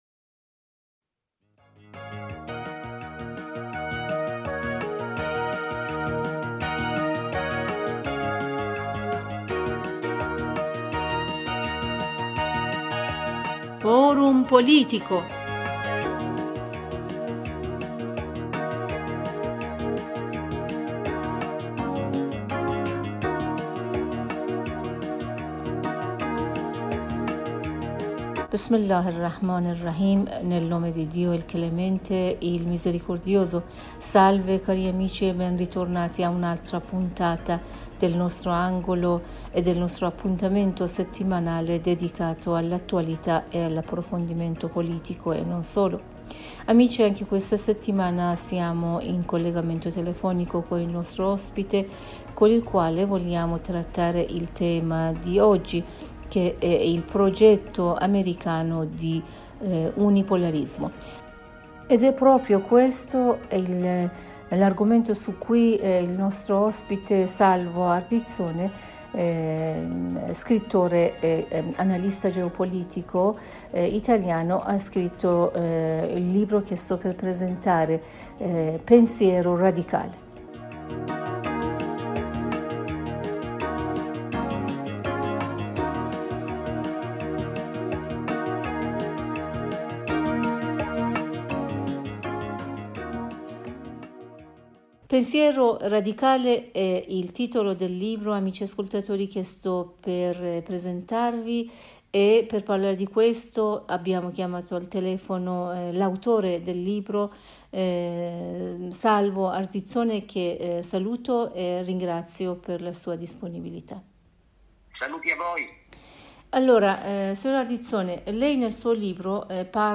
scrittore italiano in un collegamento telefonico con la Radio Italia della Voce della Repubblica islamica dell'Iran (IRIB) con il sito par...